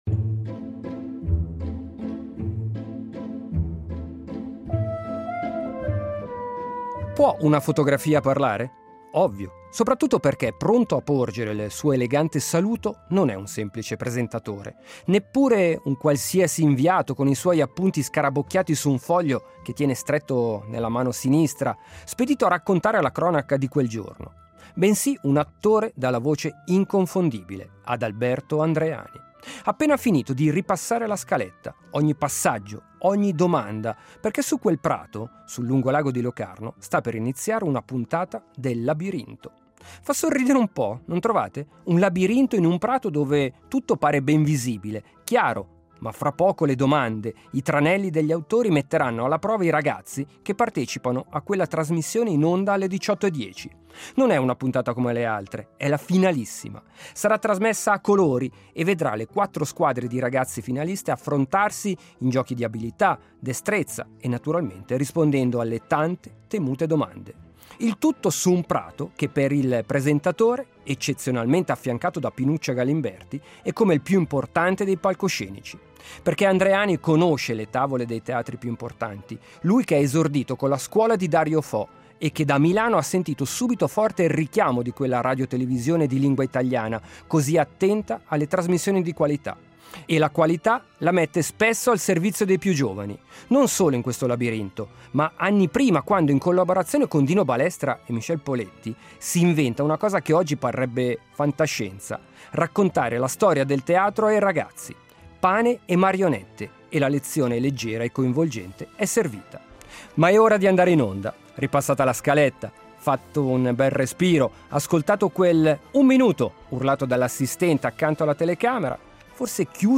Una trasmissione per ragazzi piena di simpatici tranelli